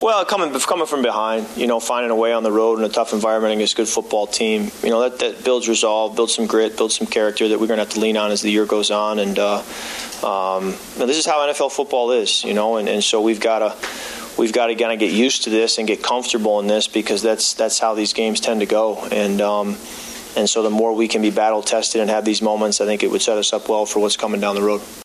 After the game, Cousins spoke about the importance of Monday night’s win for his team.